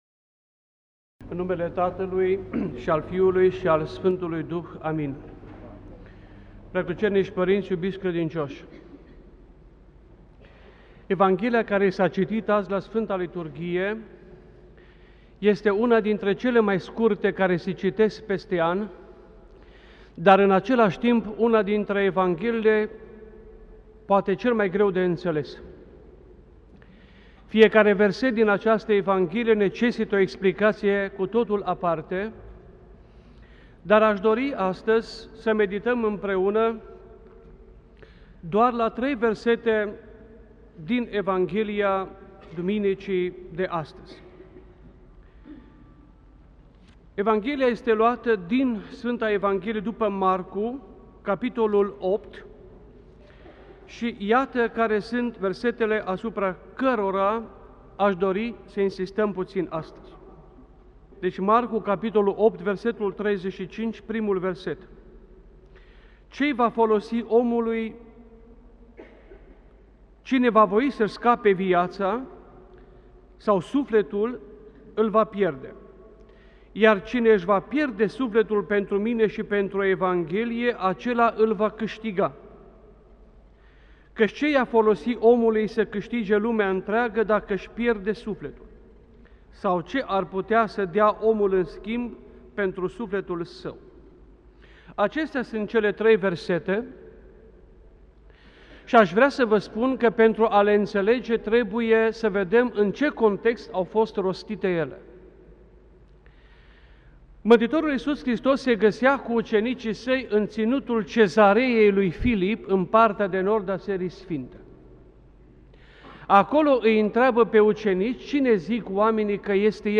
Cuvinte de învățătură Predică la Duminica după Înalțarea Sfintei Cruci